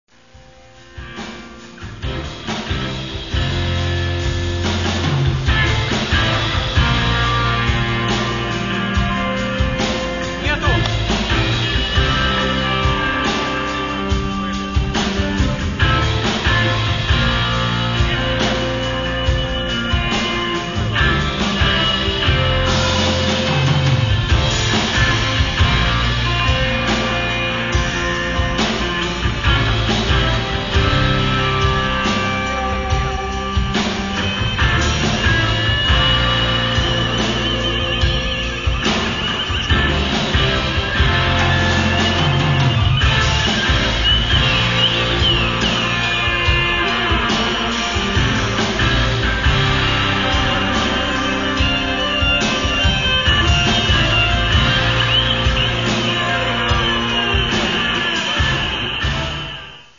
Каталог -> Джаз та навколо -> Збірки, Джеми & Live